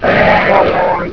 Arch-Vile